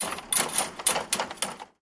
fo_chainfence_rustle_03_hpx
Chain link fence is rattled. Rattle, Chain Link Fence Metallic, Chain Link Fence